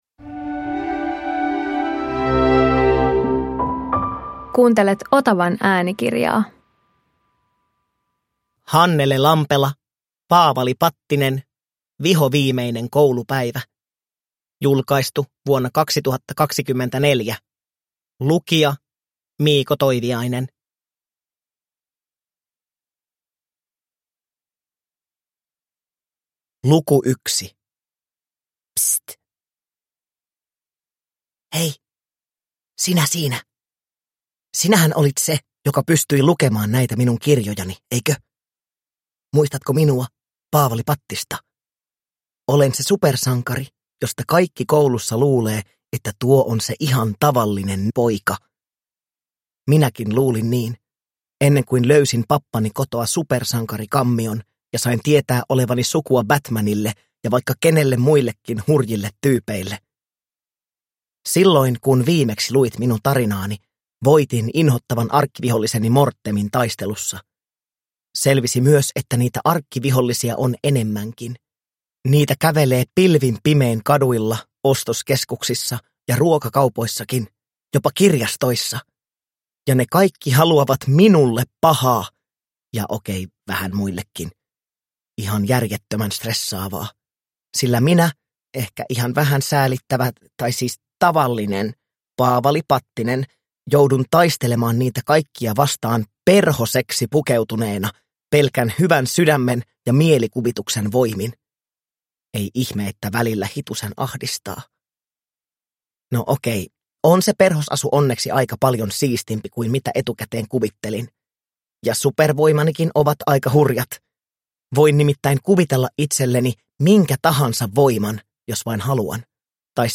Paavali Pattinen - Vihoviimeinen koulupäivä – Ljudbok